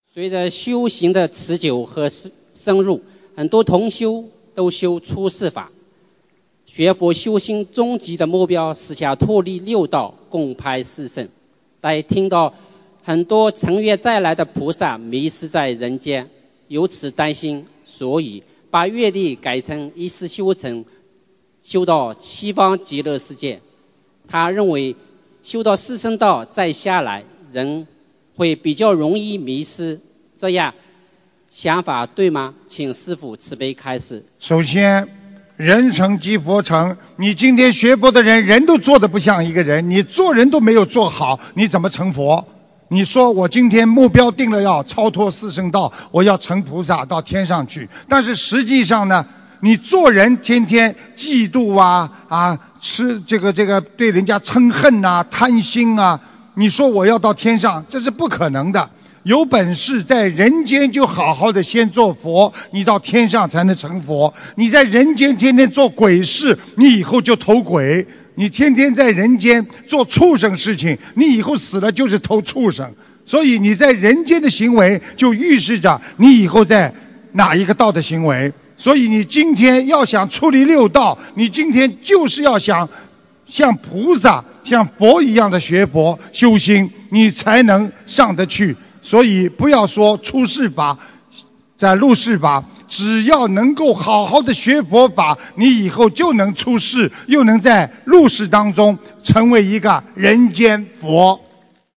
如何修才能不在人间迷失，一世修成，超脱六道┃弟子提问 师父回答 - 2017 - 心如菩提 - Powered by Discuz!